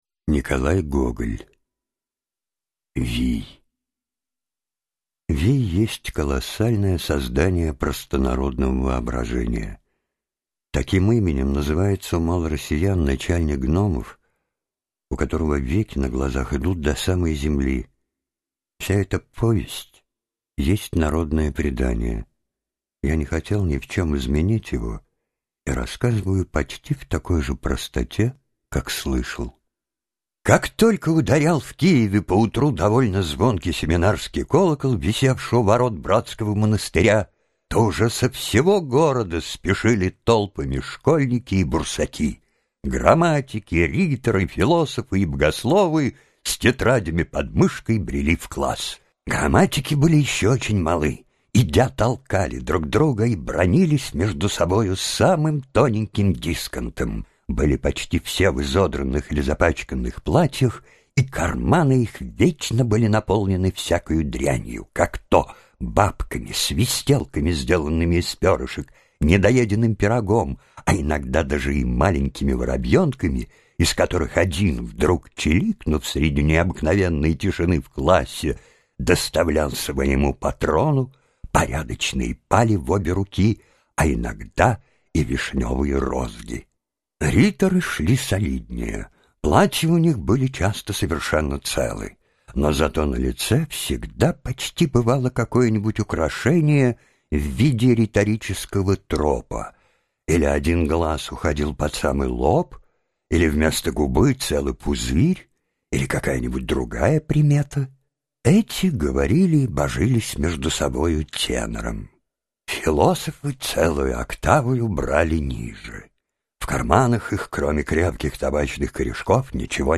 Аудиокнига Вий | Библиотека аудиокниг